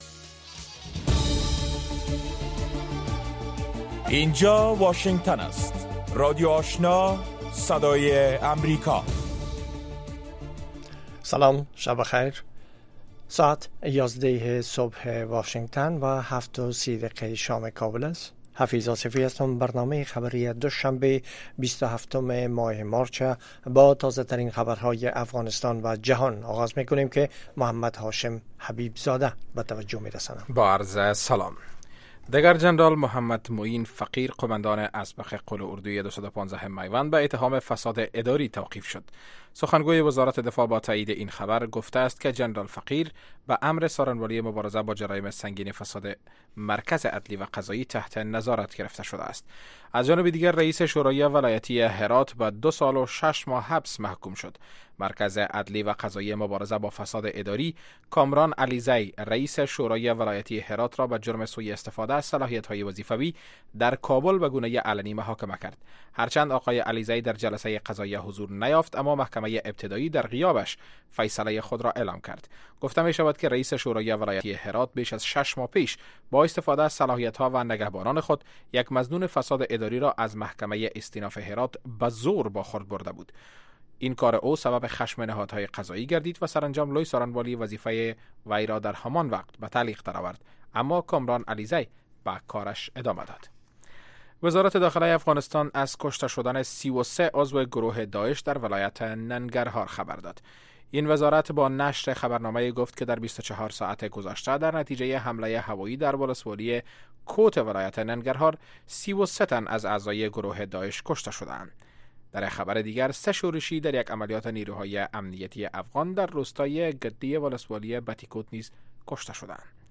اولین برنامه خبری شب